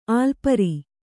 ♪ ālpari